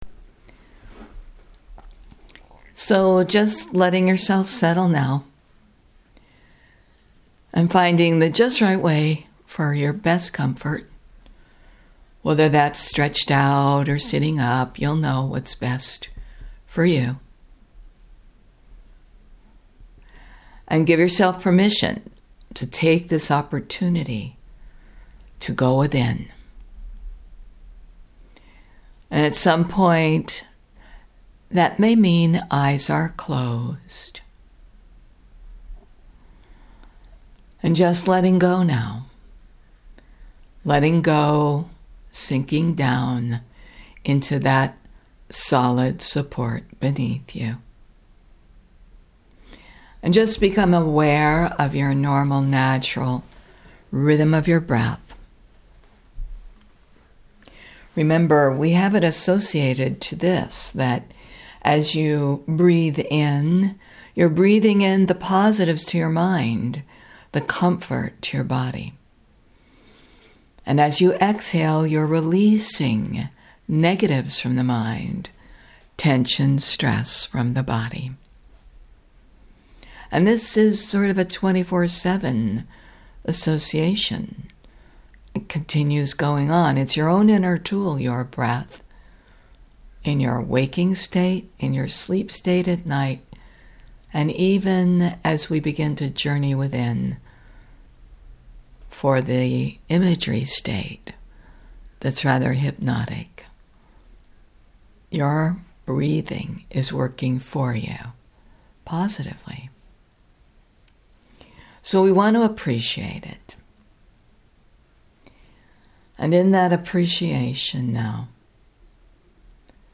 Guided Imagery recorded on November 5